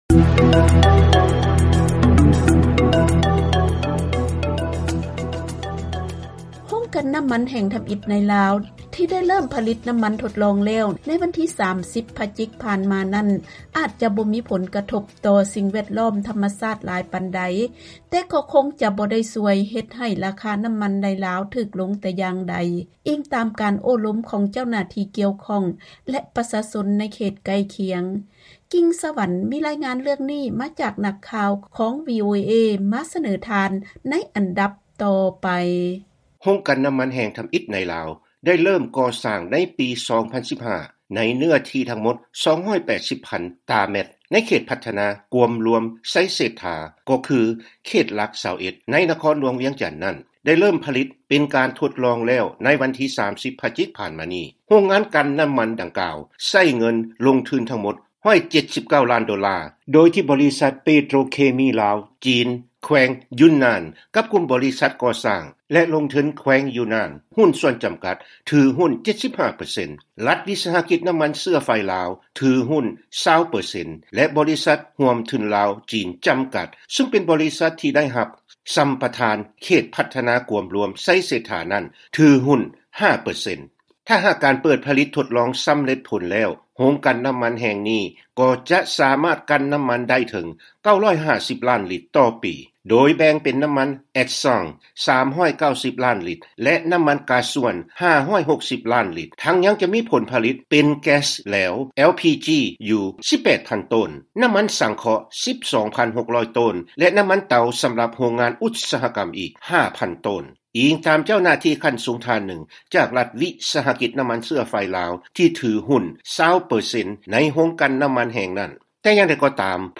ເຊີນຟັງລາຍງານຈາກ ສປປ ລາວ.